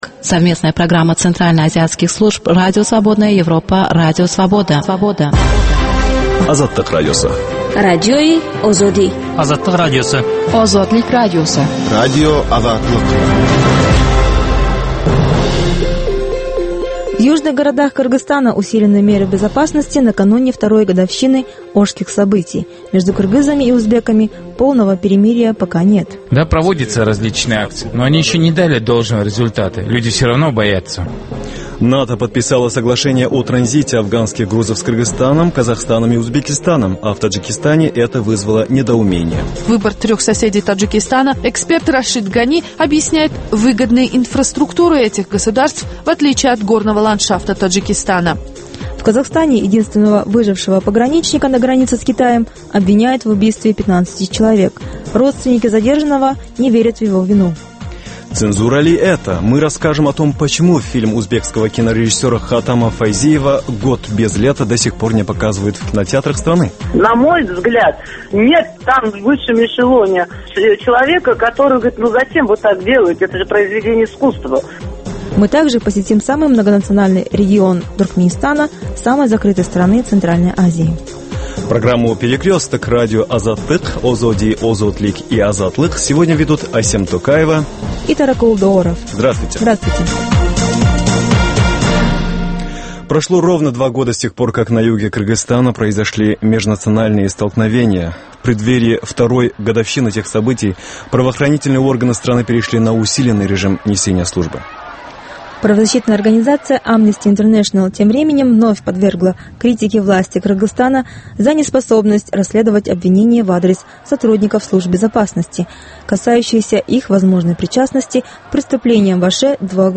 Новости стран Центральной Азии. Специальная программа на русском языке.